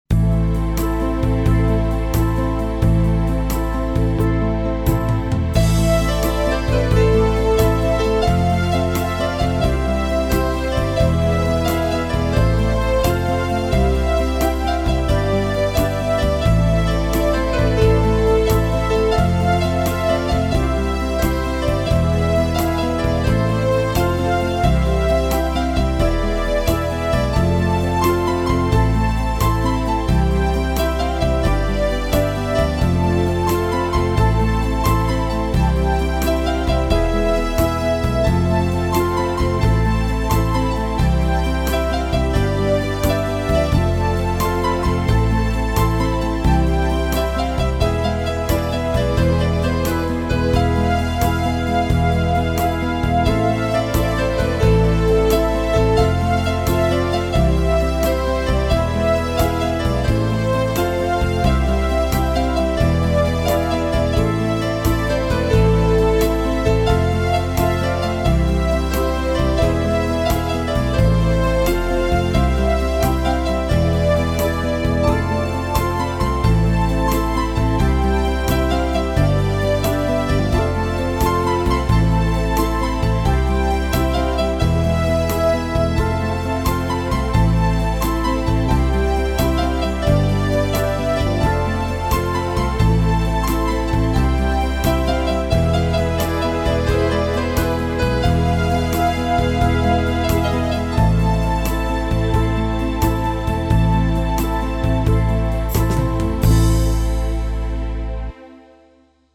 Melodierne fejler for saa vidt ikke noget, men de er helt sikkert ikke godt arrangeret.